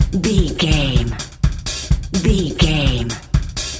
Electronic loops, drums loops, synth loops.,
Epic / Action
Fast paced
In-crescendo
Ionian/Major
Fast
epic
industrial
powerful
driving
hypnotic